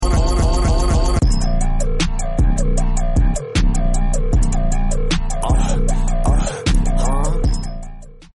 Fax Sound Effects Free Download